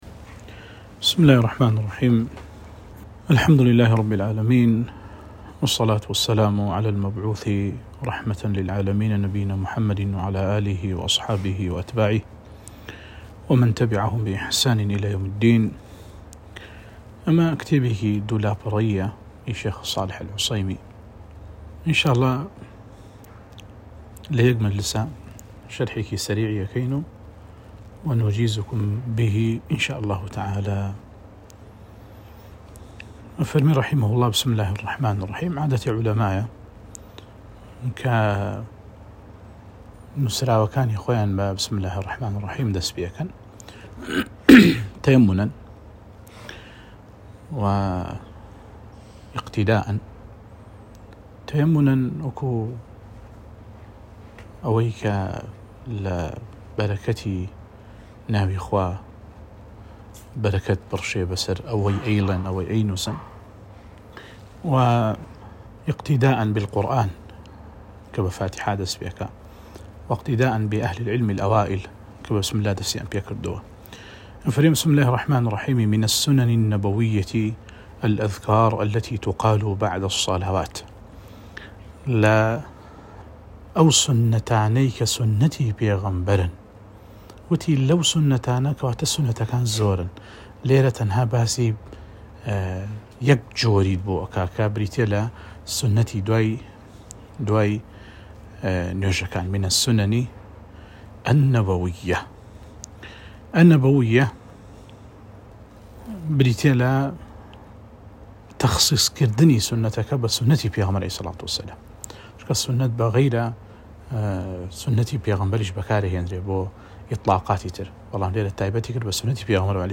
(تەنها یەك وانەیە)